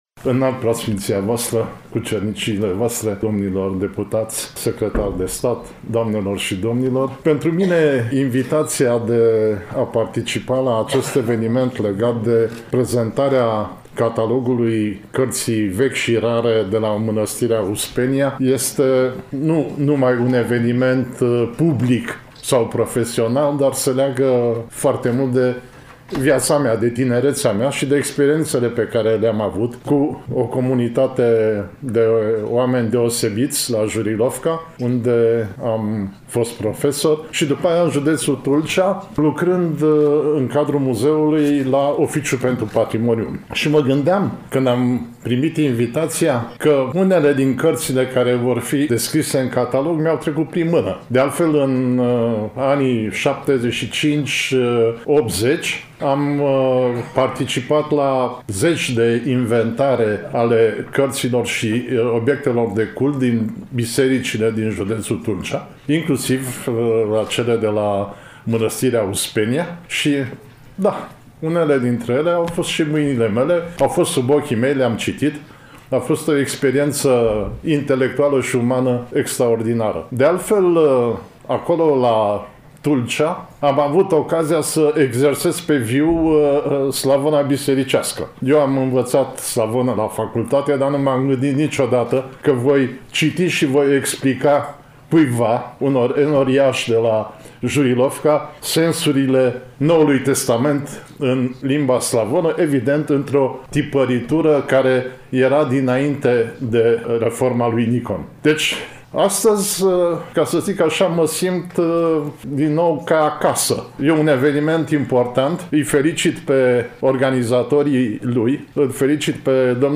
Evenimentul a avut loc, nu demult, în Amfiteatrul „I. H. Rădulescu” al Bibliotecii Academiei Române, București.